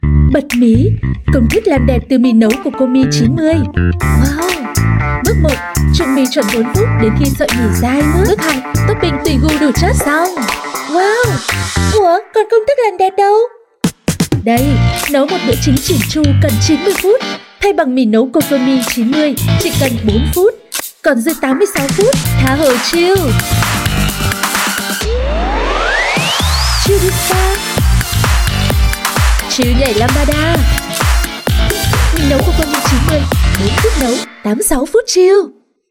VIETNAMESE NORTH FEMALE VOICES
We use Neumann microphones, Apogee preamps and ProTools HD digital audio workstations for a warm, clean signal path.